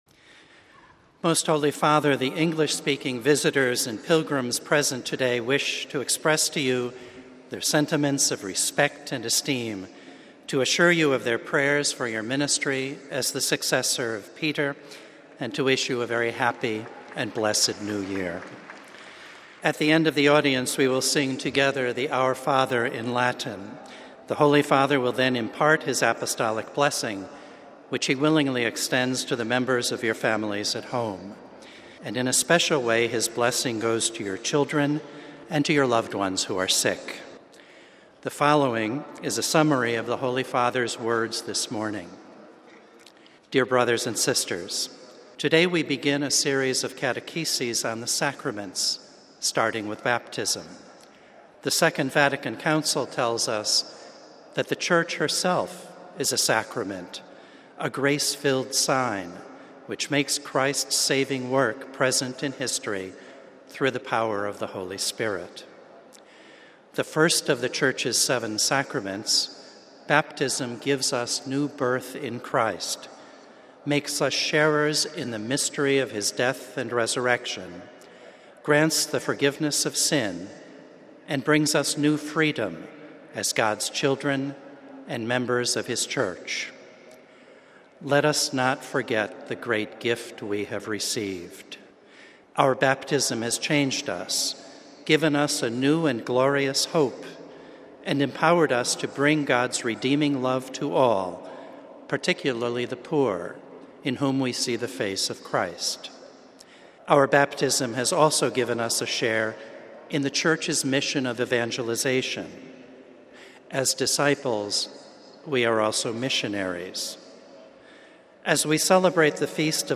The general audience of Jan. 8 was held in the open, in Rome’s St. Peter’s Square.
Basing himself on the scripture passage, Pope Francis delivered his main discourse in Italian - summaries of which were read out by aides in various languages, including in English. But first, the aide greeted the Pope on behalf of the English-speaking pilgrims.